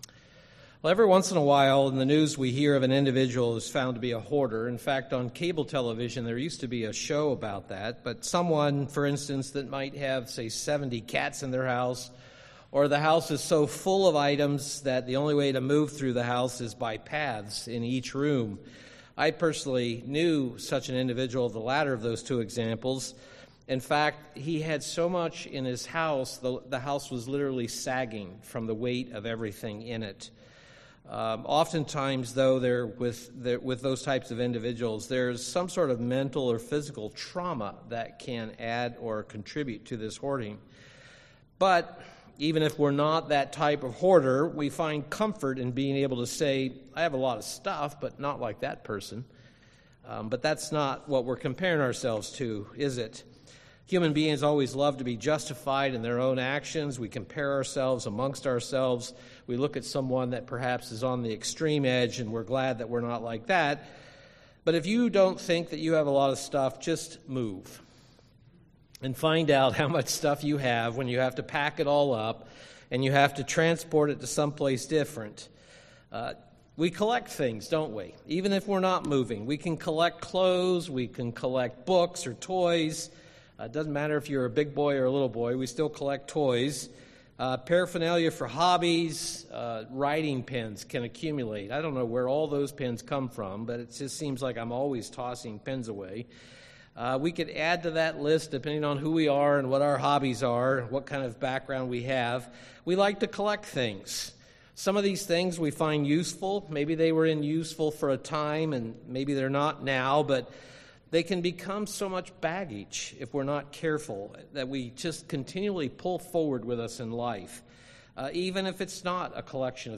Passover preparation weight UCG Sermon Studying the bible?